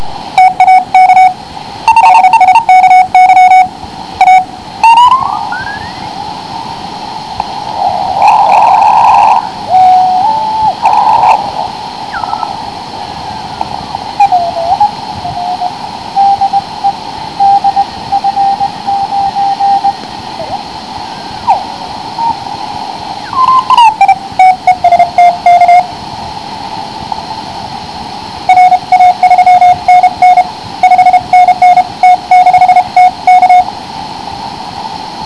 Prolaďování ( wav 780 kB )
Jinak kvalitu tónu posuzují posluchači jako vynikající. Kliksy nejsou zřetelné.